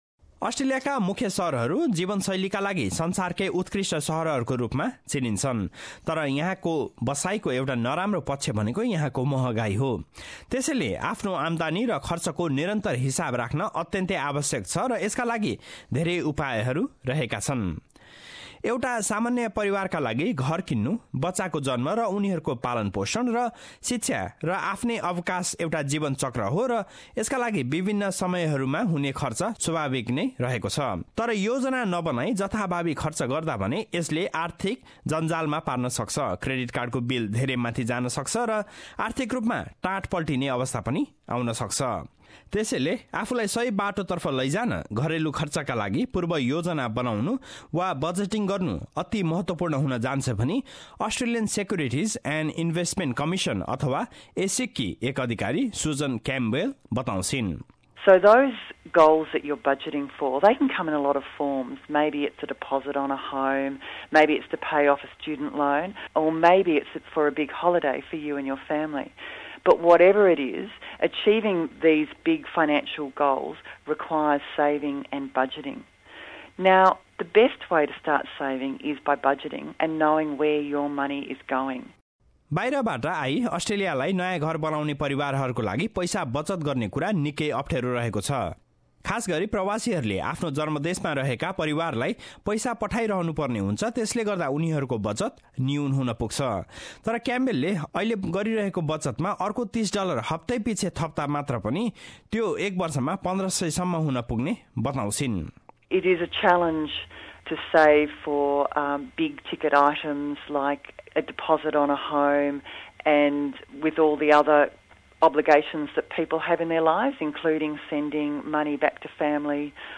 रिपोर्ट